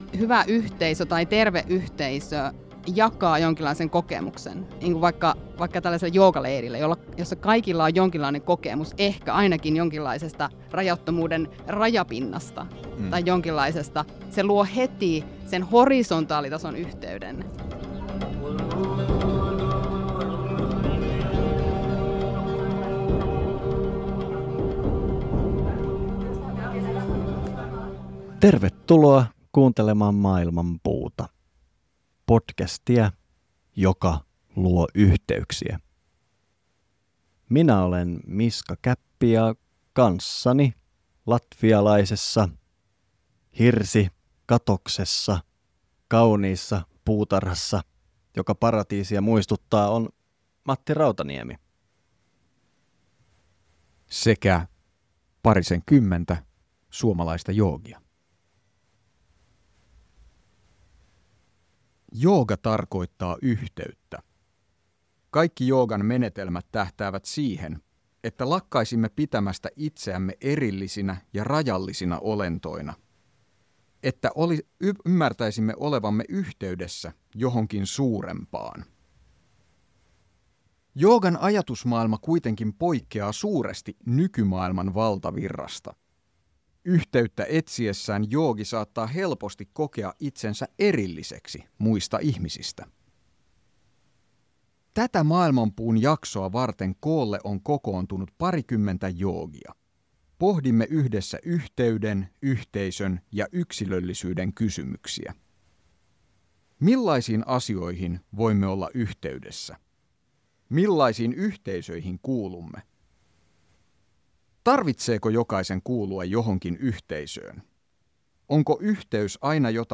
Tätä Maailmanpuun jaksoa vartens saman pöydän ääreen on kokoontunut parikymmentä joogia. Pohdimme yhdessä yhteyden, yhteisön ja yksilöllisyyden kysymyksiä.